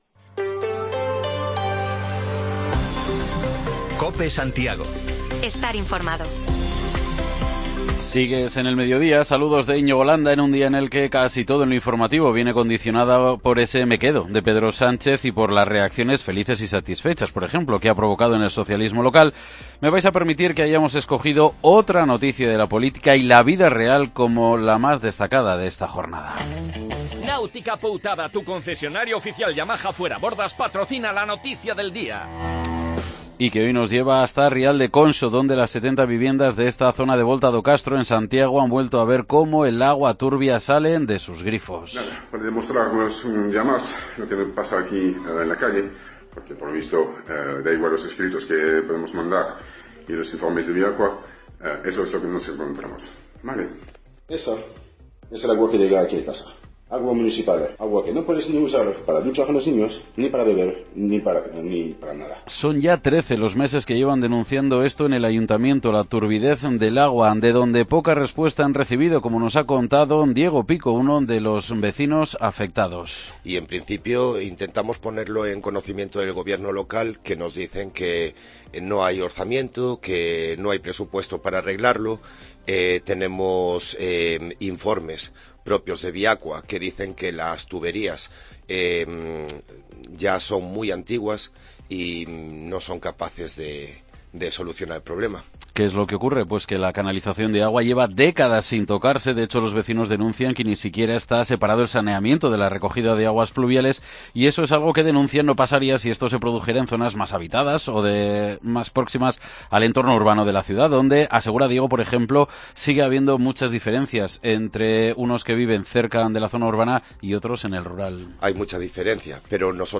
Una feriante con más de treinta años de experiencia nos cuenta cómo es su trabajo